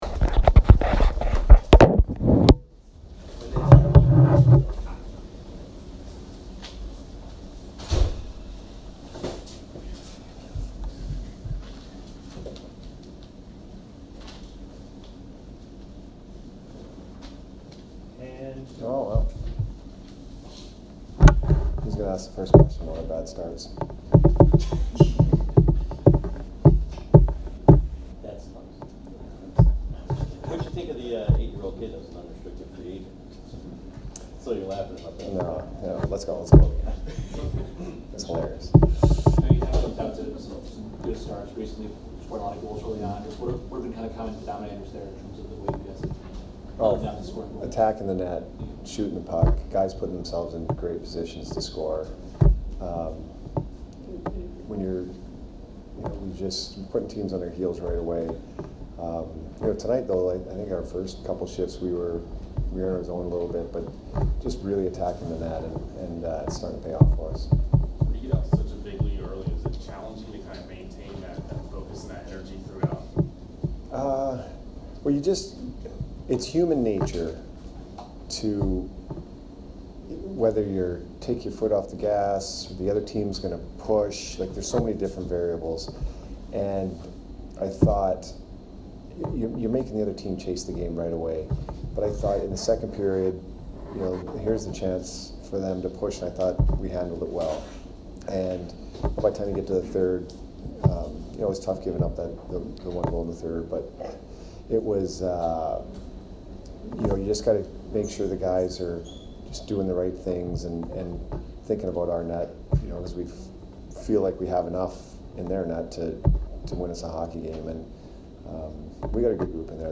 Jon Cooper post-game 11/25